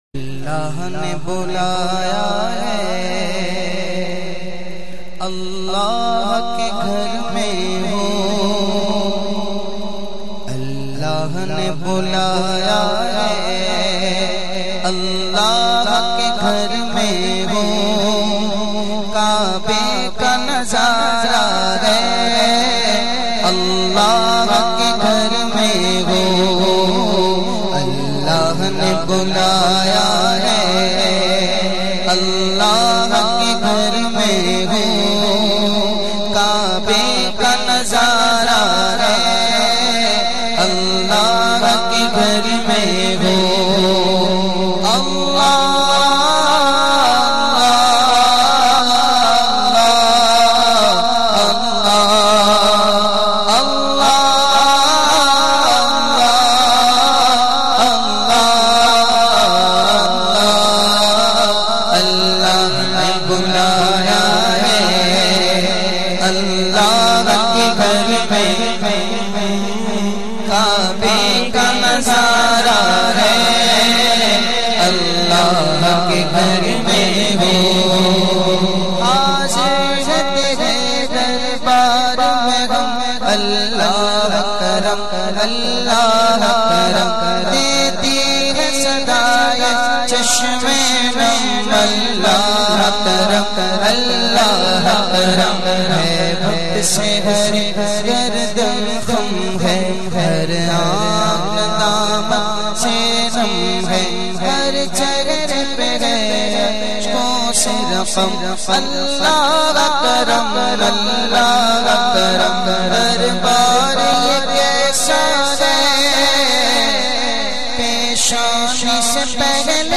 naat
Heart-Touching Voice